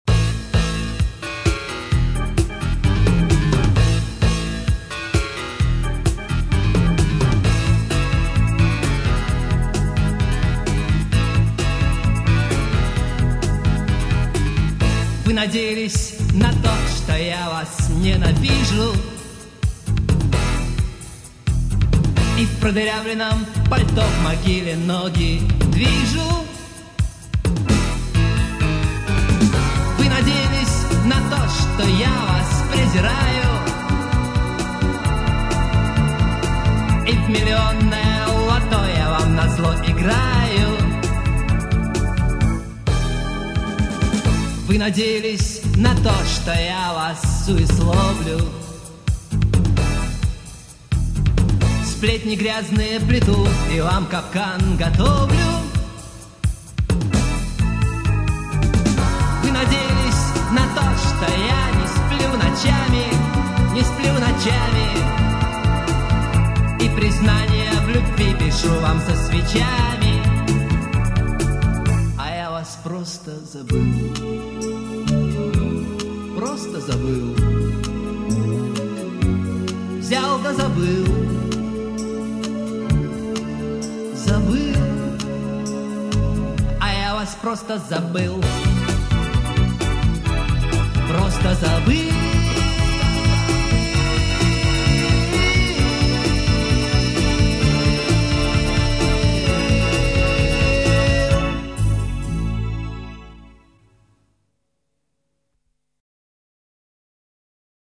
Made in Monday-City (Dushanbe).